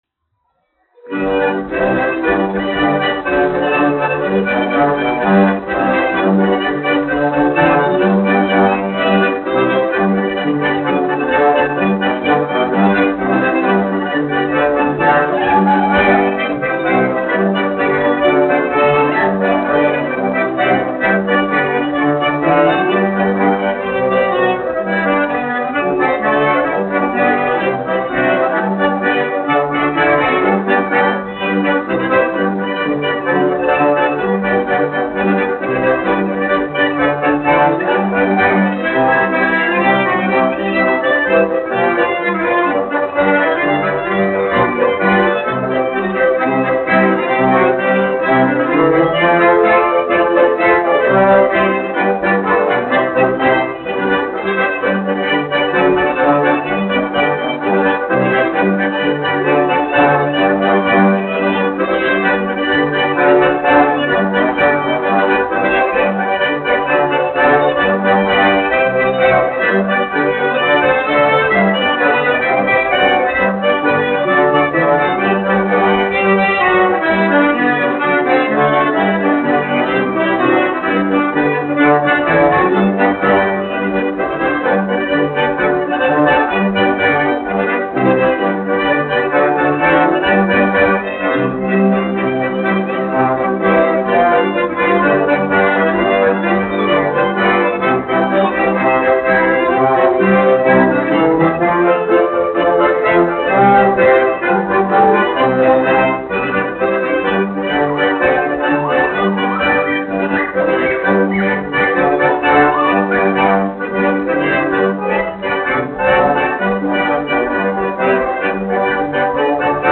1 skpl. : analogs, 78 apgr/min, mono ; 25 cm
Polkas
Populārā instrumentālā mūzika
Skaņuplate
Latvijas vēsturiskie šellaka skaņuplašu ieraksti (Kolekcija)